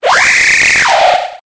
Fichier:Cri 0822 EB.ogg — Poképédia